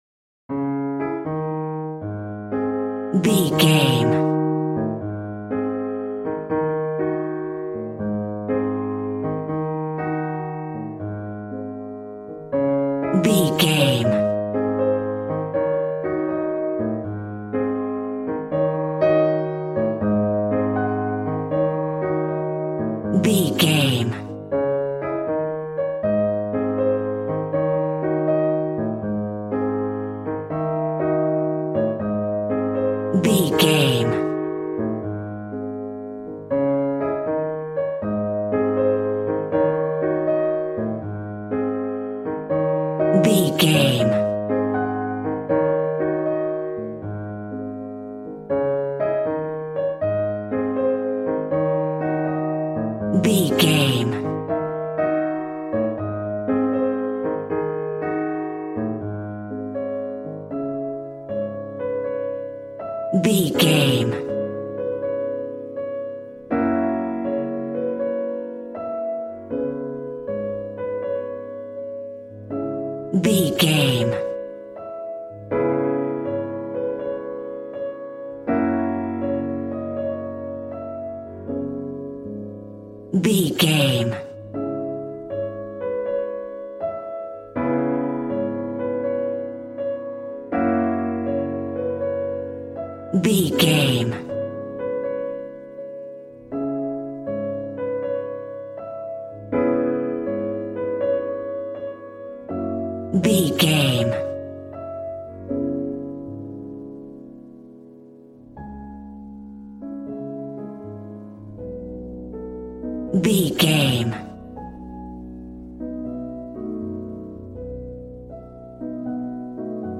Aeolian/Minor
B♭
piano
drums